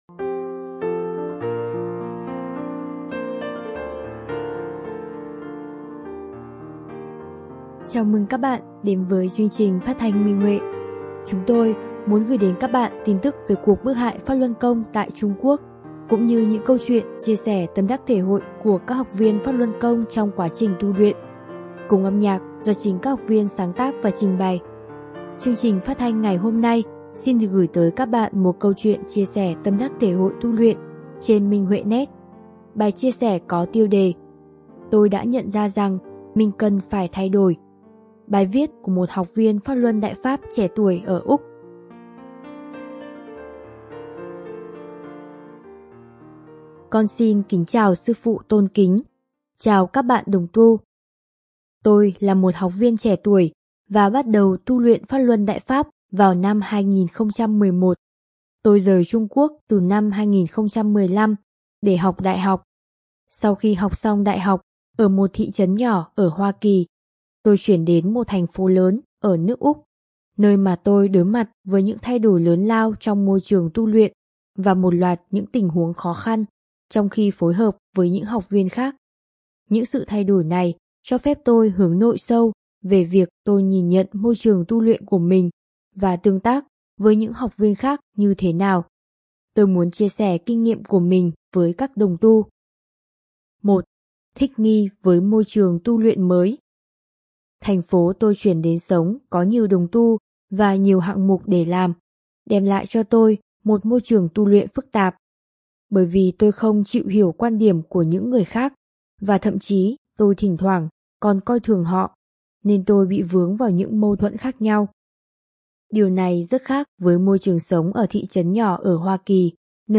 Chúng tôi muốn gửi đến các bạn tin tức về cuộc bức hại PhápLuân Côngtại Trung Quốc cũng như những câu chuyện chia sẻ tâm đắc thể hội của các học viên trong quá trình tu luyện, cùng âm nhạc do chính các học viên sáng tác và trình bày.